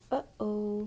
uh-oh to indicate your mistake.
uh_oh.mp3